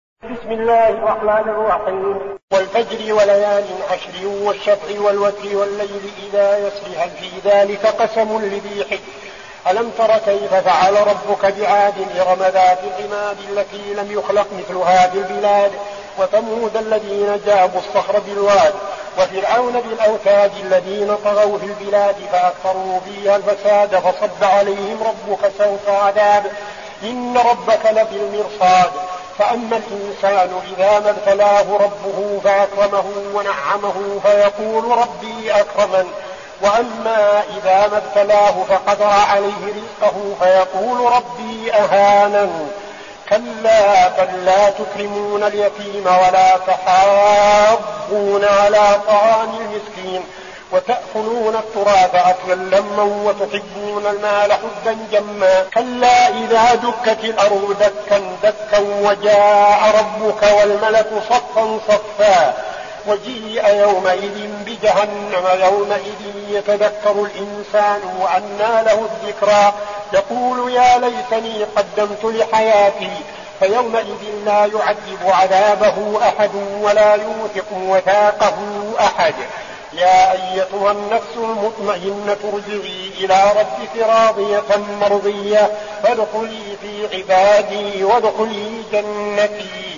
المكان: المسجد النبوي الشيخ: فضيلة الشيخ عبدالعزيز بن صالح فضيلة الشيخ عبدالعزيز بن صالح الفجر